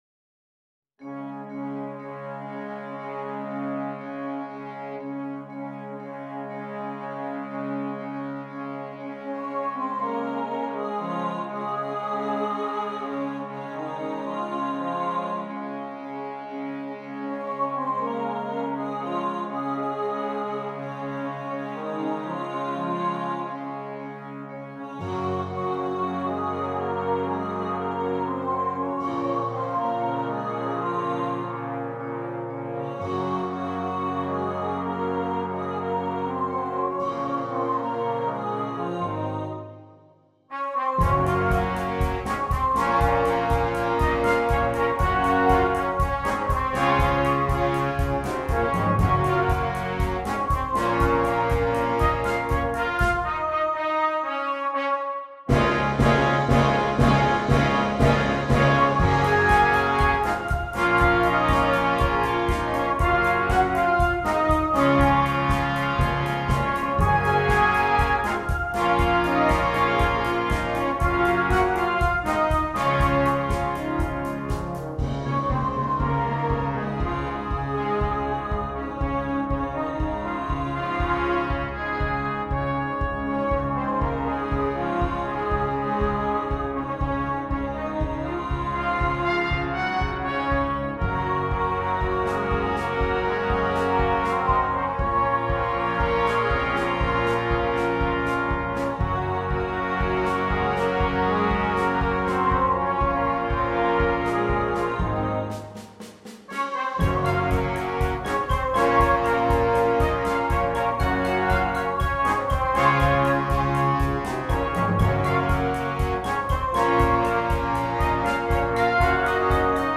Besetzung: opt. Vocal Solo & Brass Band